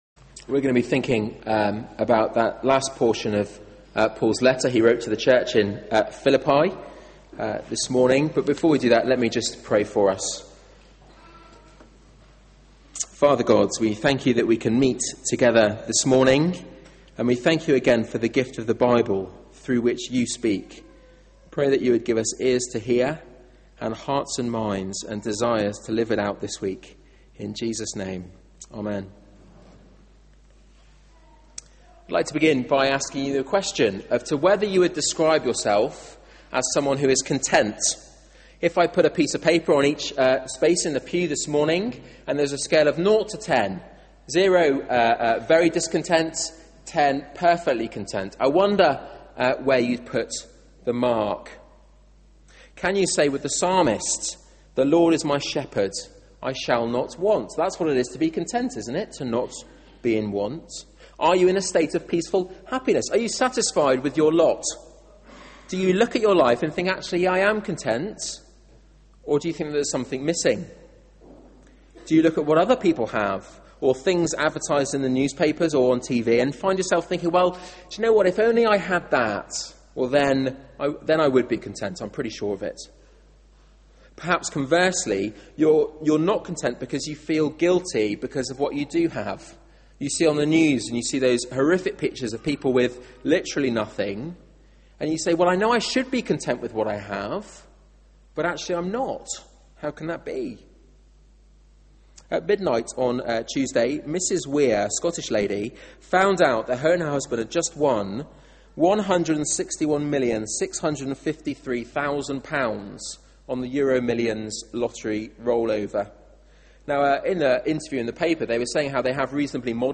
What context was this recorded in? Media for 9:15am Service on Sun 17th Jul 2011 09:15 Speaker